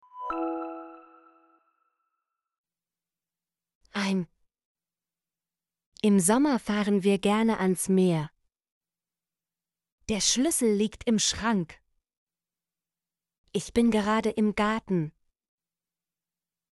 im - Example Sentences & Pronunciation, German Frequency List